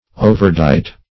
Overdight \O"ver*dight"\
overdight.mp3